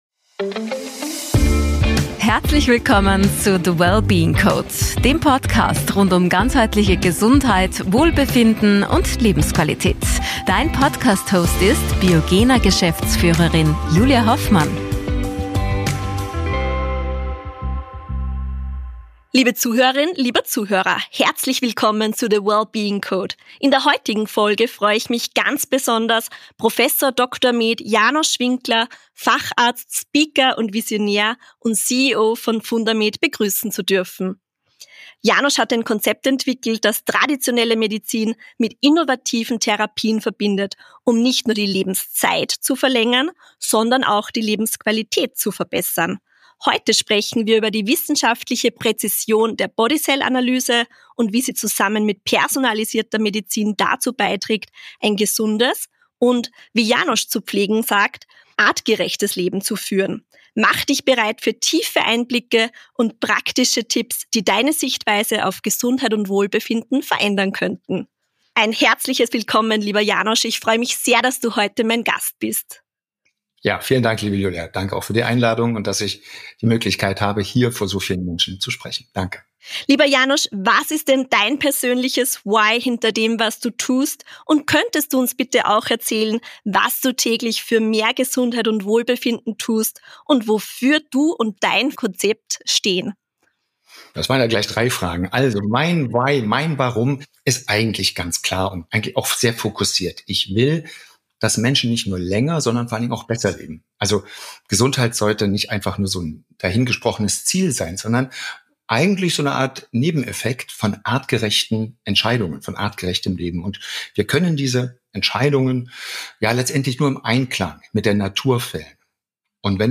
Erfahre, wie die BodyCell-Analyse tiefgehende Einblicke in deine Gesundheit gibt, warum Goodevity über reine Langlebigkeit hinausgeht und wie du durch gezielte Maßnahmen dein Wohlbefinden nachhaltig steigerst. Ein Gespräch voller wissenschaftlicher Präzision, praxisnaher Tipps und neuer Perspektiven auf das Thema Gesundheit.